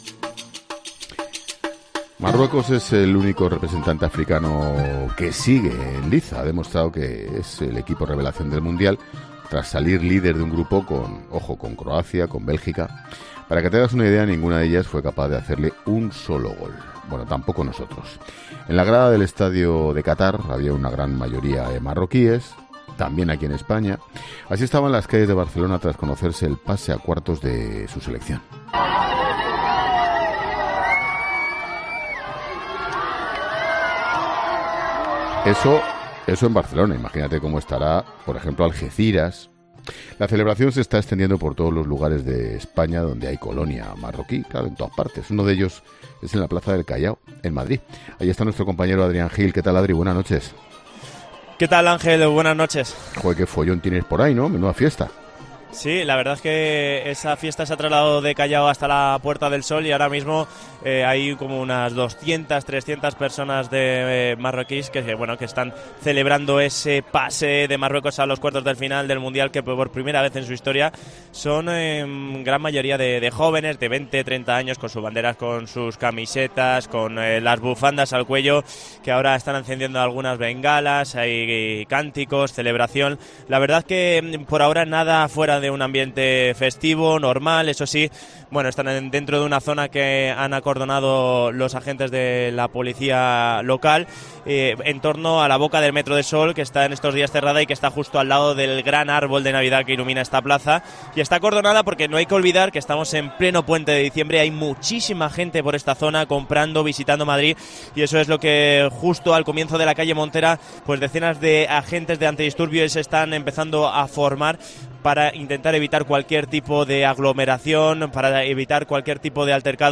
'La Linterna' de COPE ha salido a la calle para contar la celebración eufórica de la población marroquí de nuestro país, la cual se ha desarrollado con normalidad en la capital, sin lamentar altercados. Hay cánticos y celebración, mientras se encienden algunas bengalas.